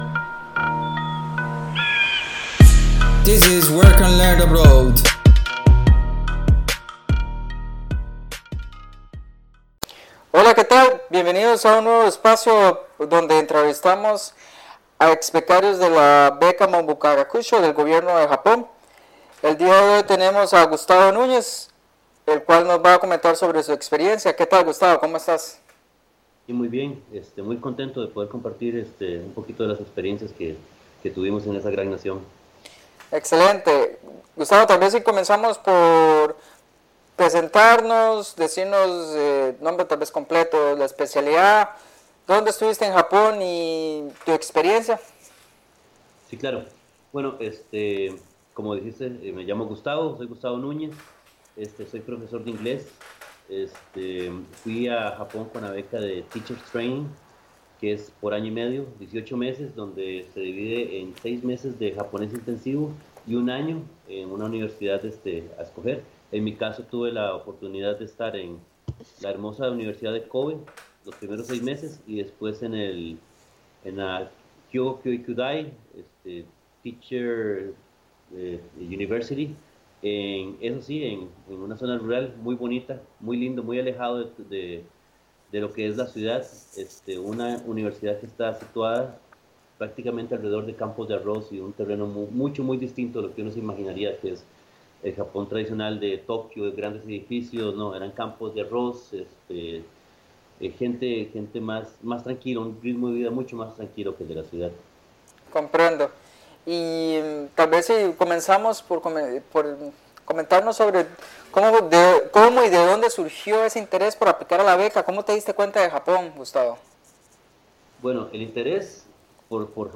41 Estudiar en el extranjero Entrevista beca Monbusho Teacher Training